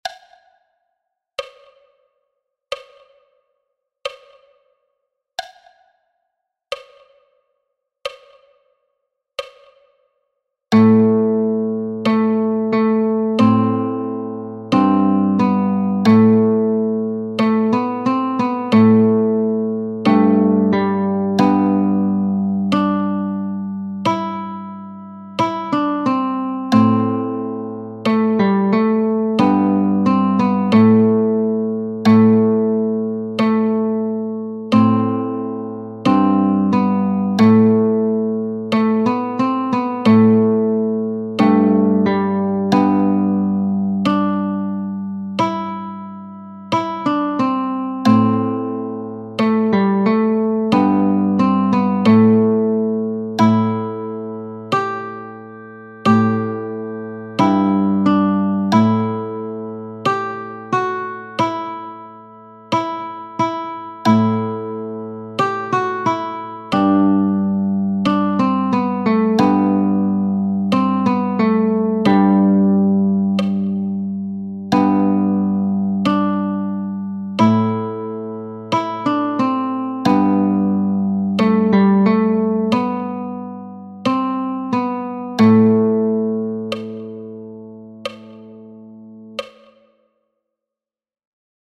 Liederbuch mit 38 Bearbeitungen von Liedern aus dem Mittelalter und der Renaissance für die Gitarre.
Die Sounds zu den Stücken sind langsam aufgenommen, ein gut hörbares Metronom unterstützt das rhythmische Verständnis.